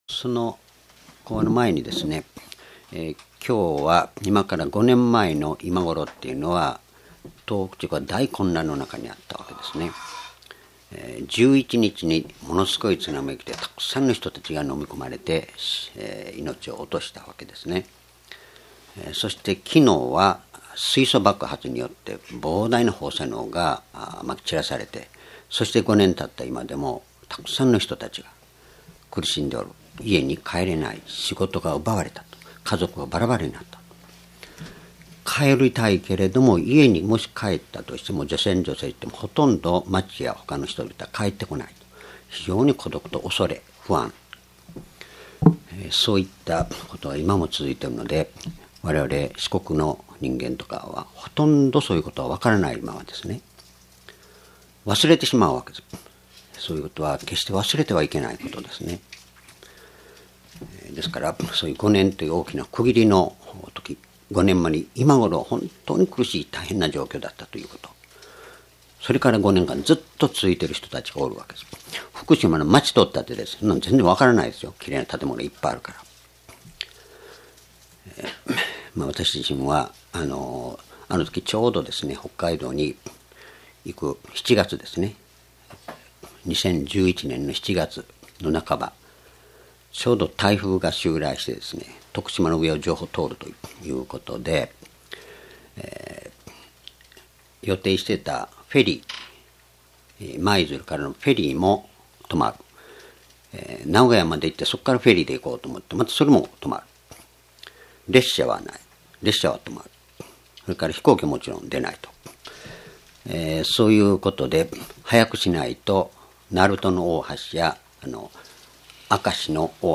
主日礼拝日時 ２０１６年４月１７日 聖書講話箇所 マタイ福音書5章1-3 「心の貧しい人は、幸いである」 ※視聴できない場合は をクリックしてください。